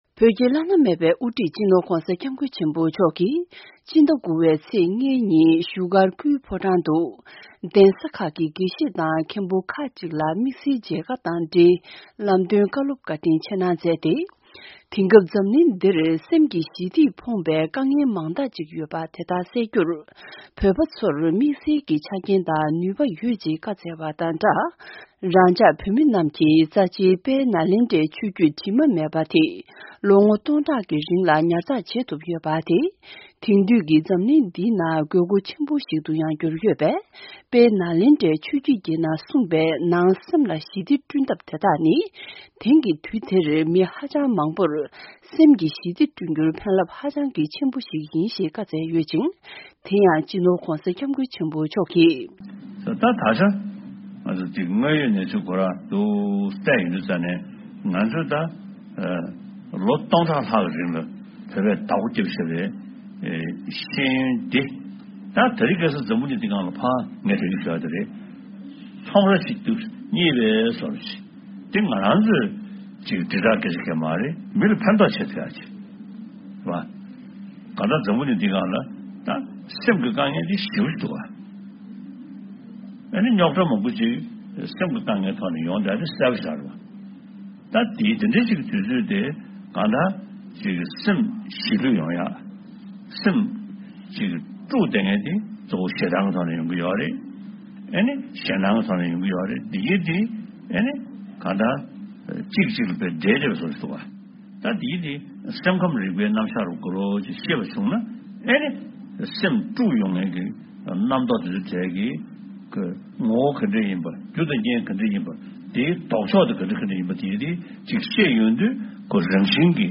བོད་ཀྱི་བླ་ན་མེད་པའི་དབུ་ཁྲིད་༧སྤྱི་ནོར་༧གོང་ས་༧སྐྱབས་མགོན་ཆེན་པོ་མཆོག་གིས། ཕྱི་ཟླ་༩པའི་ཚེས་༥ལྔའི་ཉིན། བཞུགས་སྒར་དུ་སྐུའི་ཕོ་བྲང་ནང་། གདན་ས་ཁག་གི་དགེ་བཤེས་དང་མཁན་པོ་ཁག་ཅིག་ལ་དམིགས་བསལ་མཇལ་ཁ་དང་། ལམ་སྟོན་བཀའ་སློབ་བཀྲིན་ཆེ་གནང་མཛད་དེ།